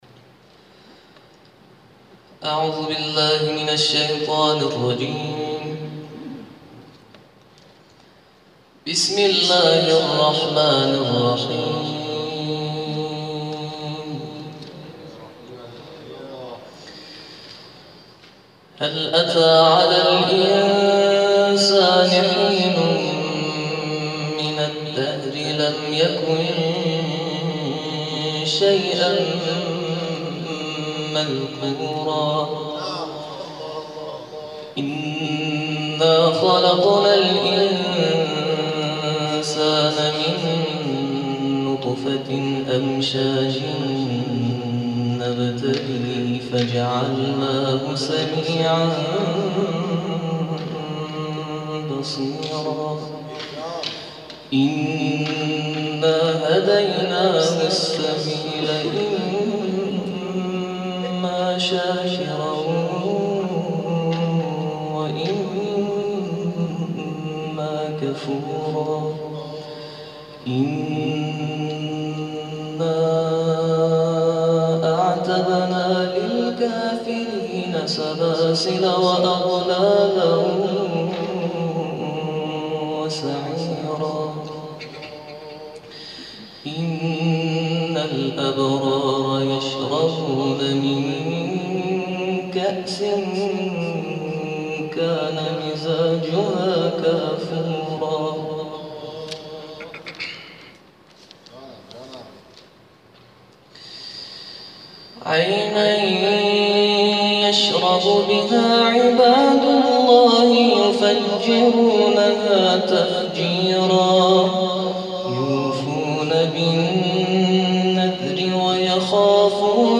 ترتیل سوره انسان